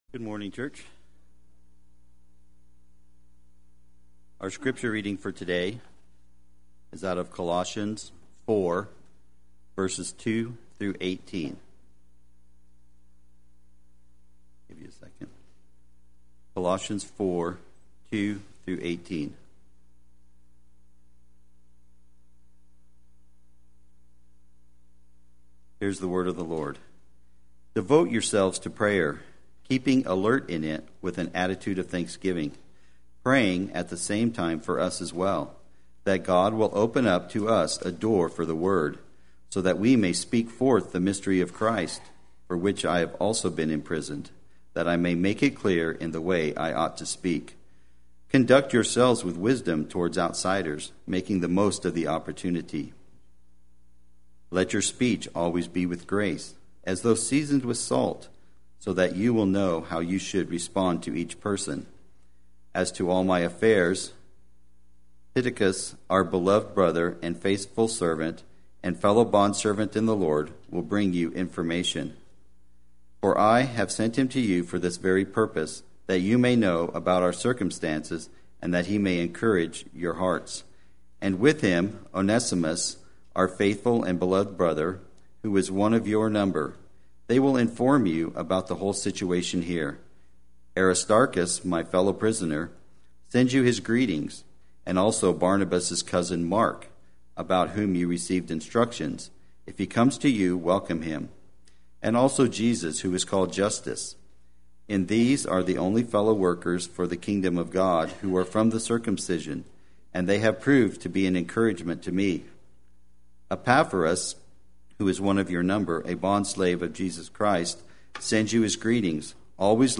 Play Sermon Get HCF Teaching Automatically.
and Consolations Sunday Worship